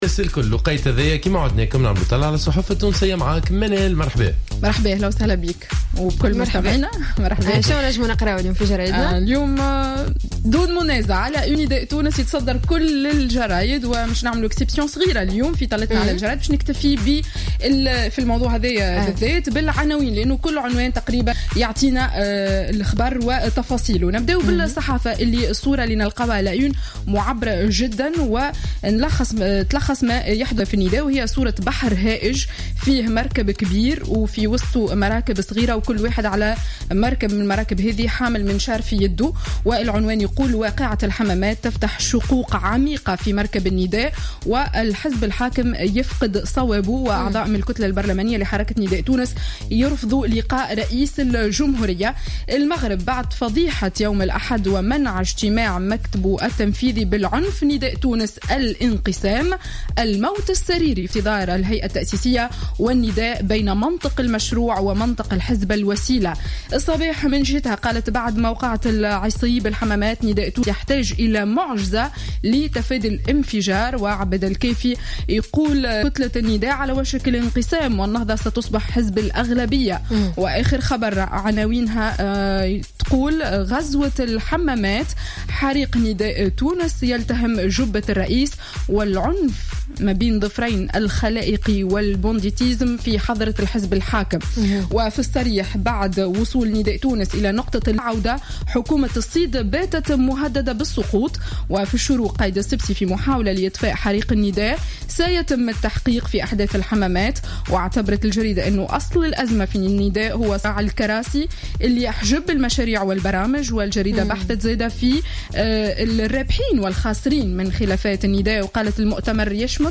Revue de presse du mardi 3 novembre 2015